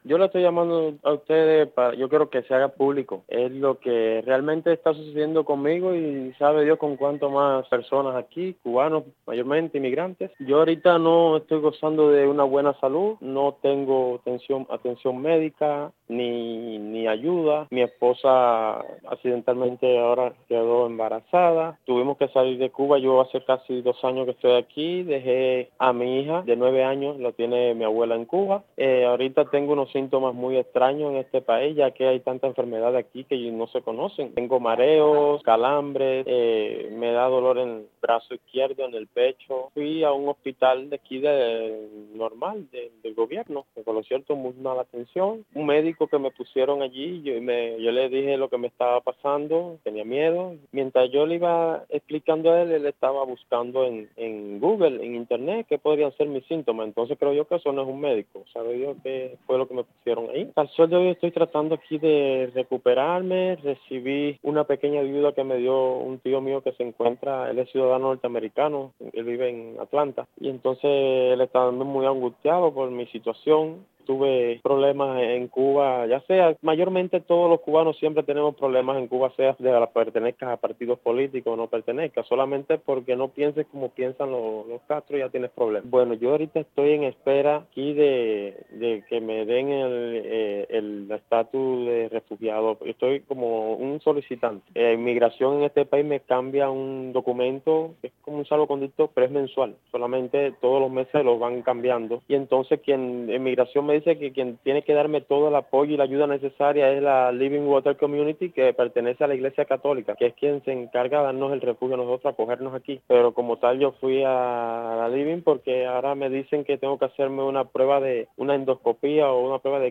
Declaraciones de migrante cubano